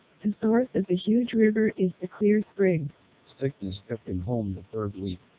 Below, you can listen to a short fragment of heavily noisy English speech after passing through MELPe and TWELP vocoders, with NPP (Noise Pre-Processor) and NCSE disabled and enabled, respectively.